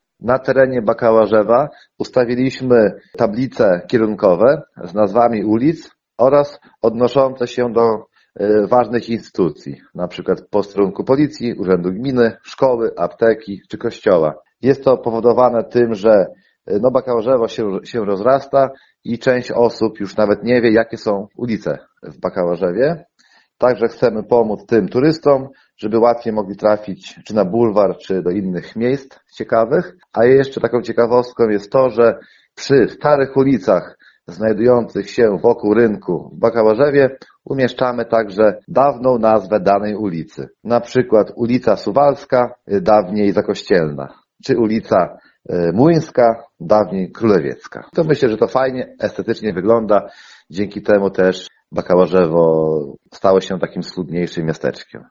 O szczegółach mówi Tomasz Naruszewicz, wójt Bakałarzewa.